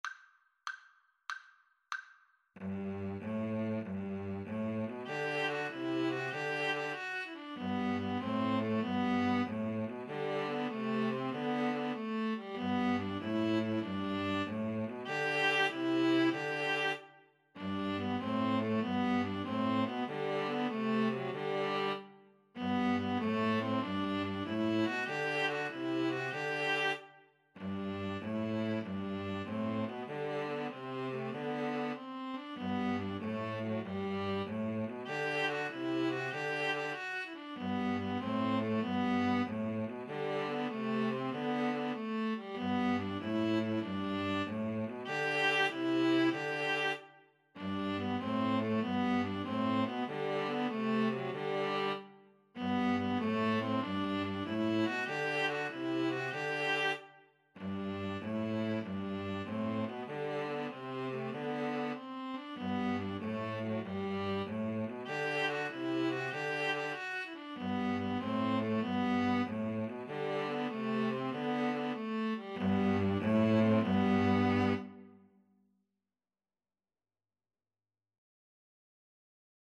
Classical Trad. Sumer is icumen in (Summer is a-coming in) String trio version
3/8 (View more 3/8 Music)
G major (Sounding Pitch) (View more G major Music for String trio )
Happily .=c.96